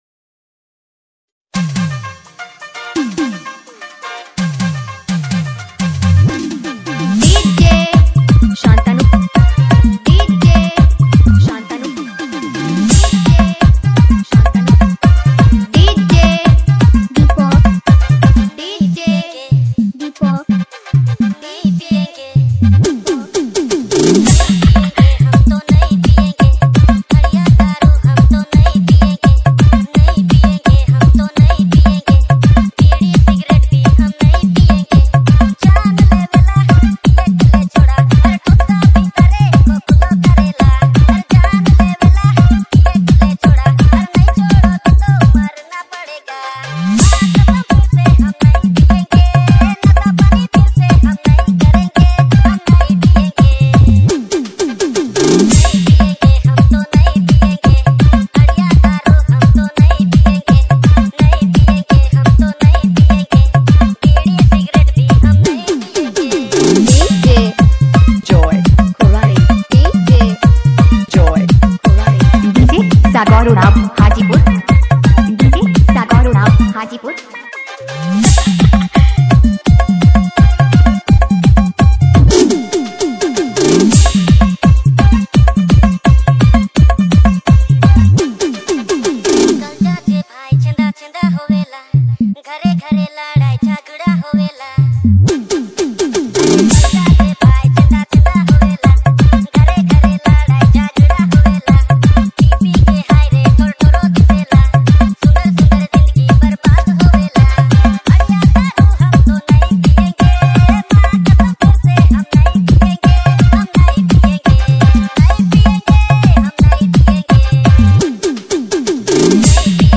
Remix Nagpuri Dj Songs Mp3 2022